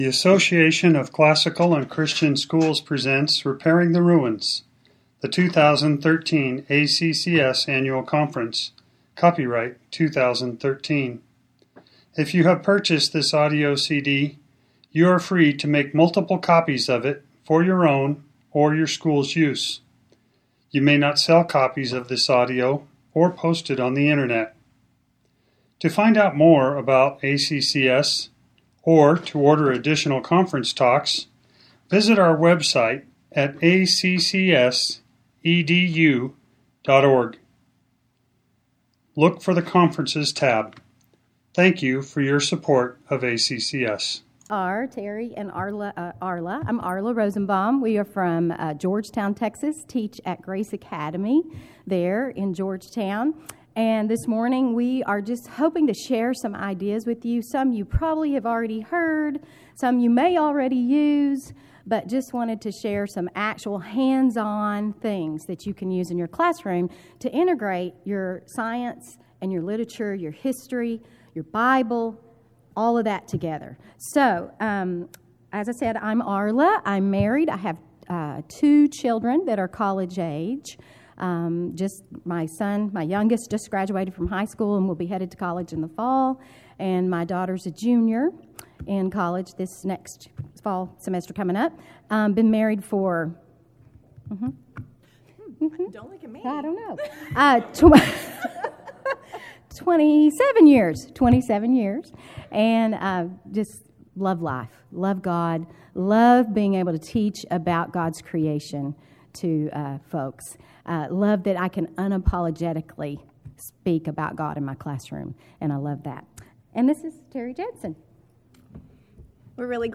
2013 Foundations Talk | 0:50:50 | All Grade Levels, Literature, Science
Jan 19, 2019 | All Grade Levels, Conference Talks, Foundations Talk, Library, Literature, Media_Audio, Science | 0 comments
The Association of Classical & Christian Schools presents Repairing the Ruins, the ACCS annual conference, copyright ACCS.